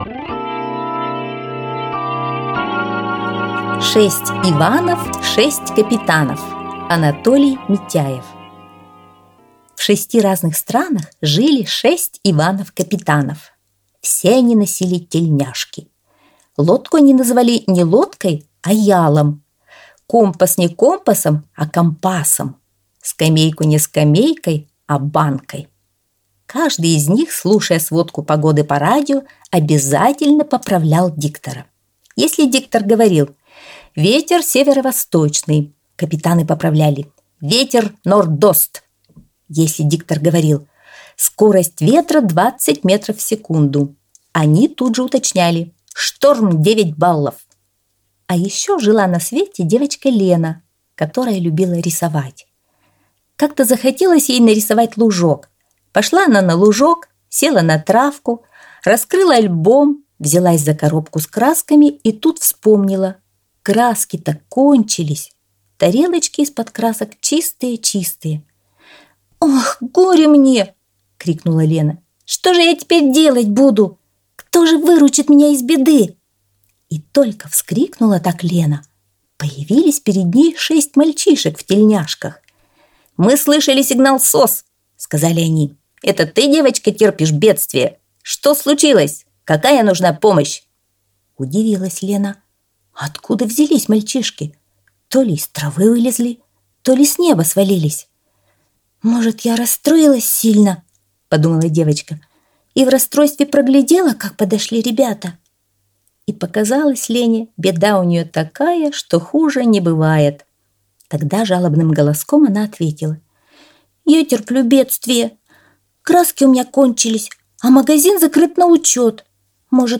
Аудиорассказ «Шесть Иванов — шесть капитанов»